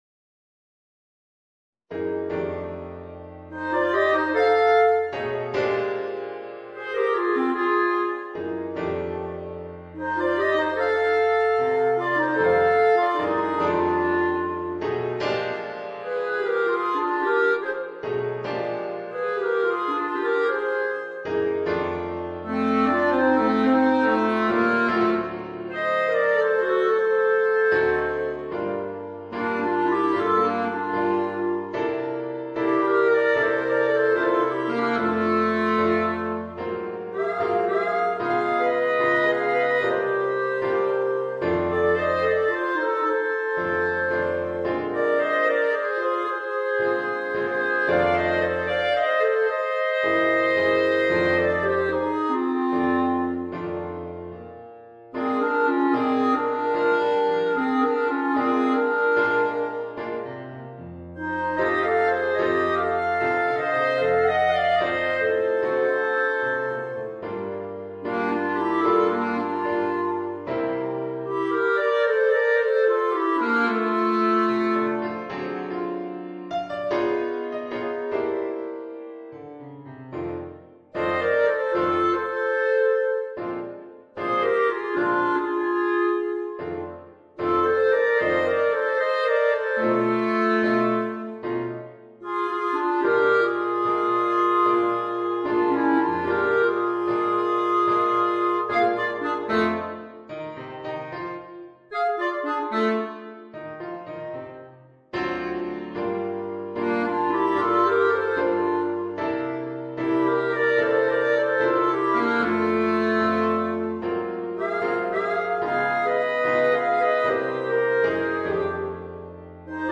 Voicing: 2 Clarinets w/ Audio